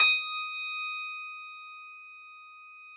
53s-pno18-D4.aif